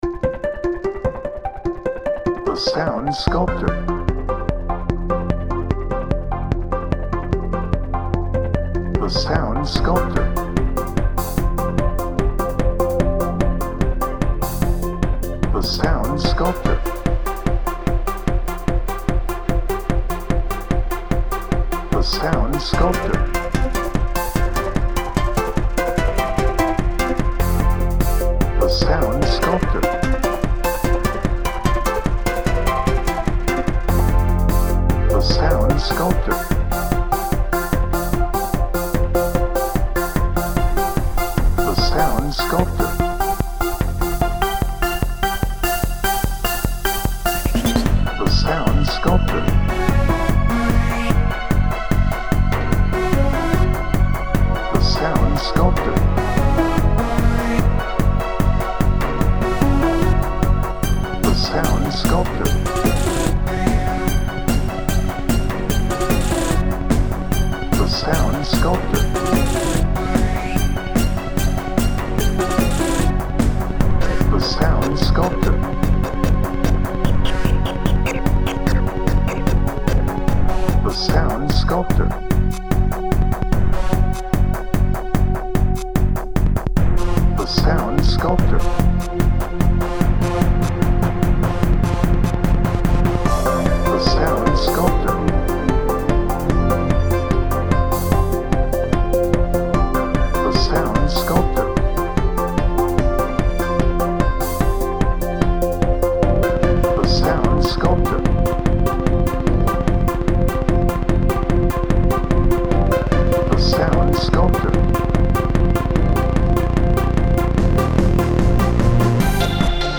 Bright
Electronic
Happy
Positive